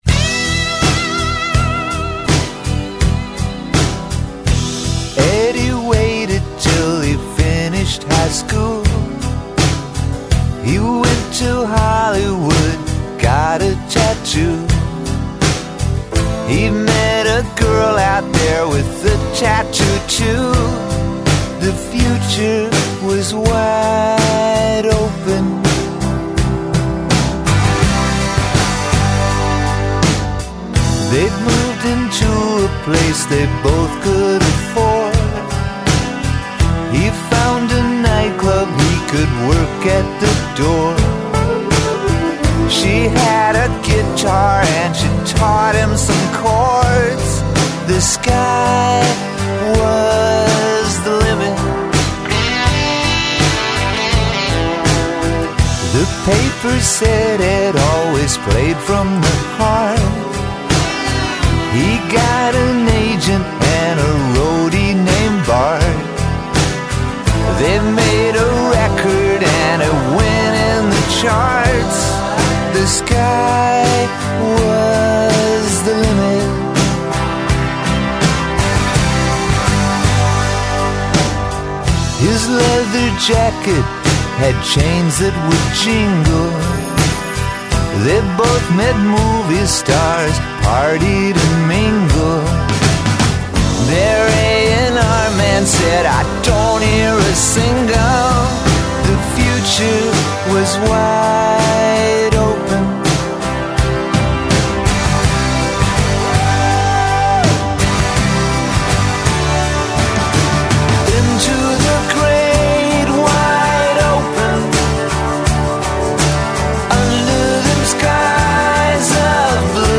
Instead, the song ends on a repeated chorus.